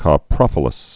(kŏ-prŏfə-ləs)